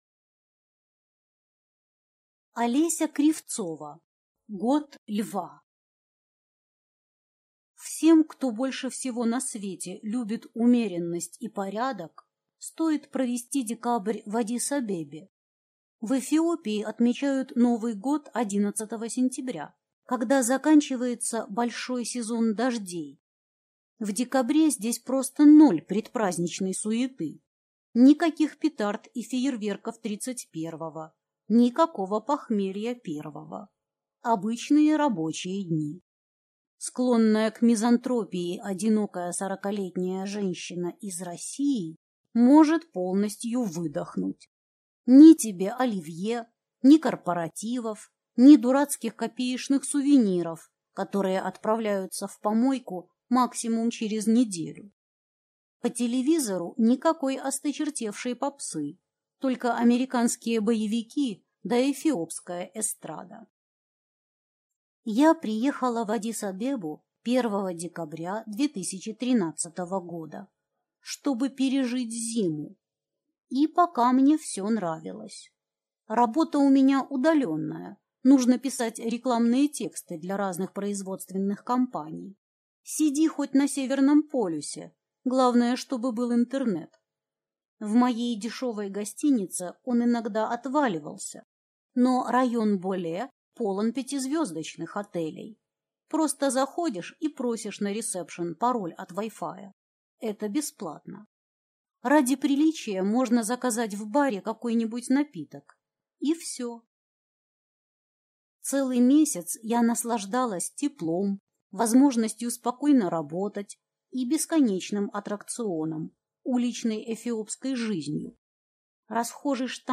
Аудиокнига Год Льва | Библиотека аудиокниг